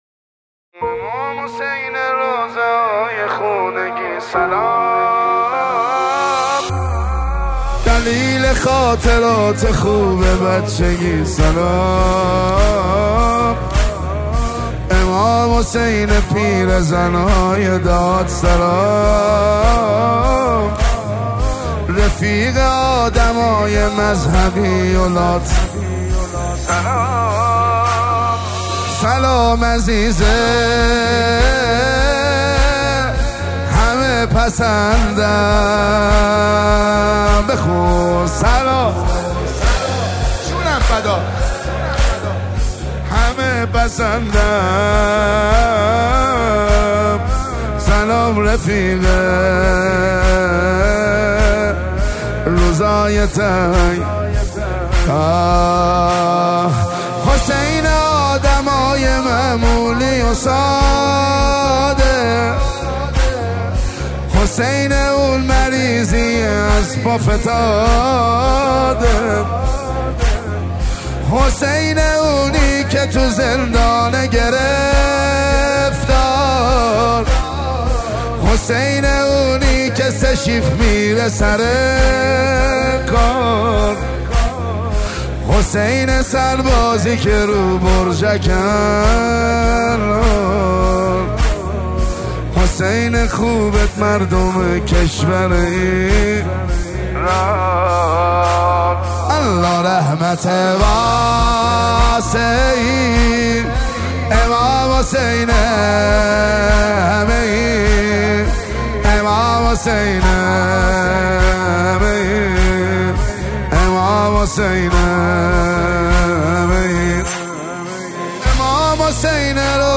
مداحی ماه محرم مداحی امام حسین